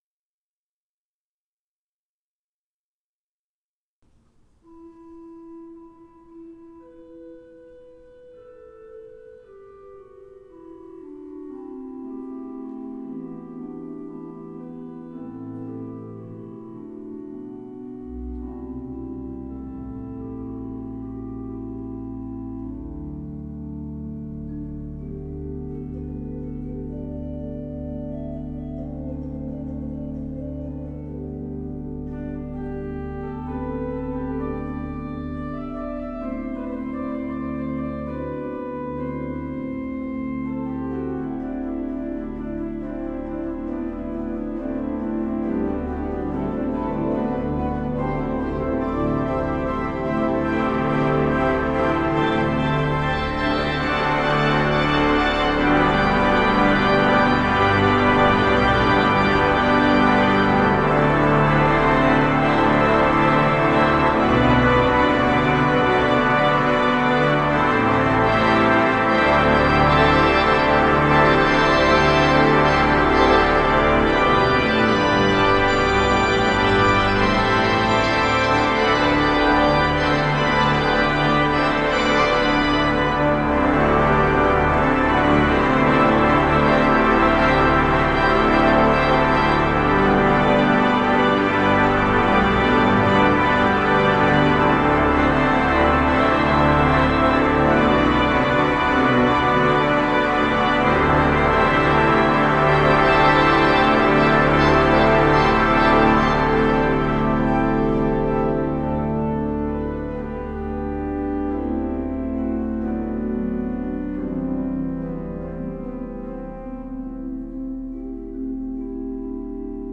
Orgelnachspiel
Orgelnachspiel 2.So_.n.Tr_.mp3